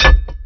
metalHeavyOnStone_end.WAV